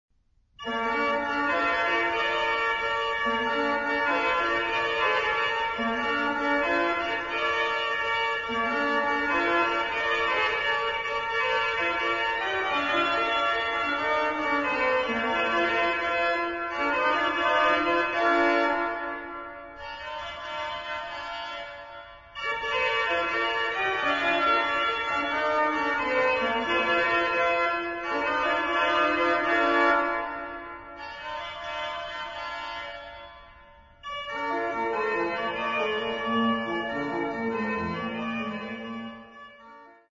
音響はオルガンの美しいパイプと石造りの建築が産みだすのだ、という事を痛感する。
場所：聖ボニファシウス教会（オランダ、メデンブリック）